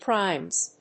/praɪmz(米国英語)/